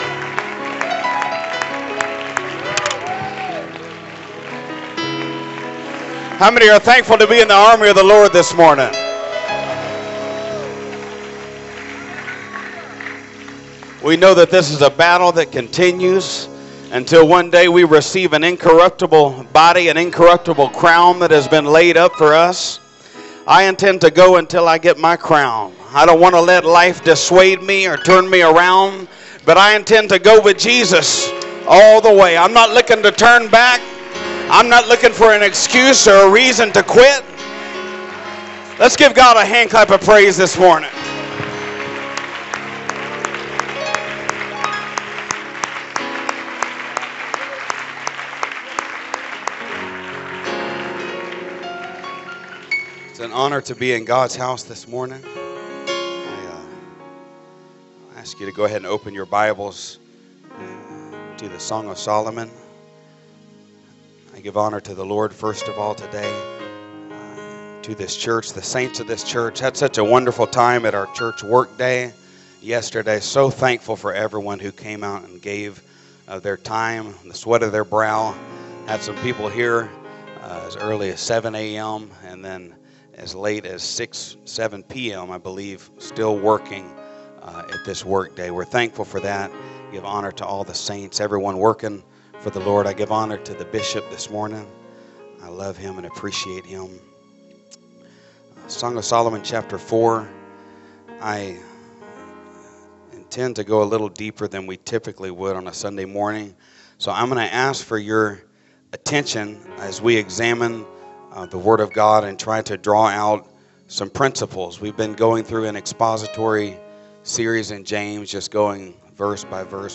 First Pentecostal Church Preaching 2022